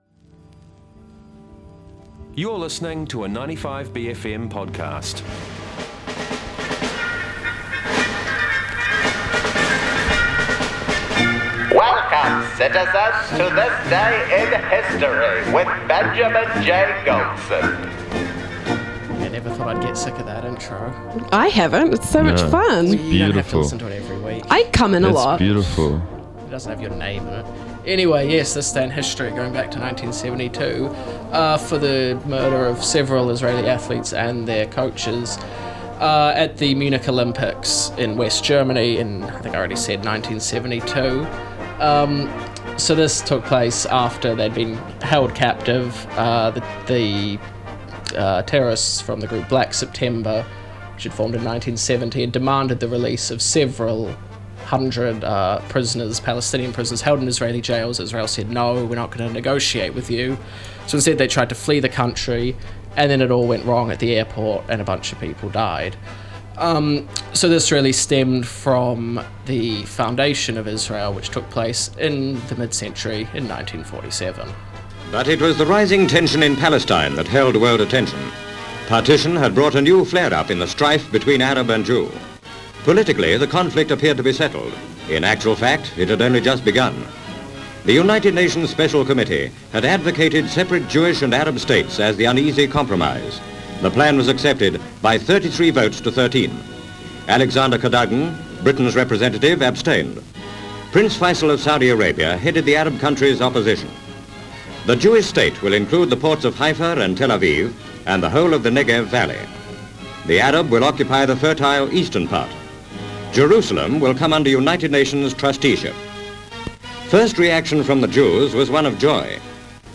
bFM's daily News & Current Affairs show
and a chat with National Party MP Jami-Lee Ross.